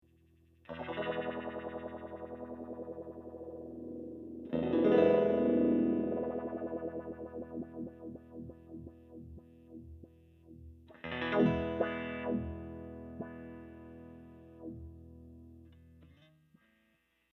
The sweep modes are ÒchoppyÓ and Òsmooth,Ó which refer to how quickly the sweep travels along the frequency range.
Sweeping the Speed control:  Clock Trigger, Choppy Mode